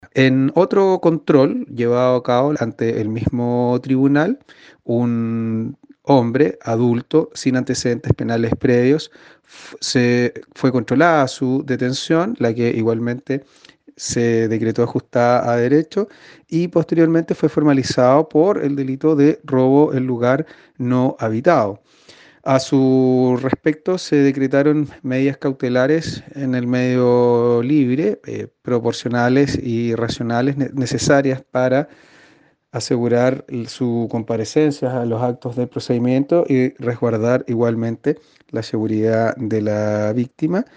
Sostuvo el fiscal que un tercer individuo fue imputado por el delito de robo en lugar no habitado, quien también deberá cumplir una pena en el medio libre quedando sujeto a medidas cautelares.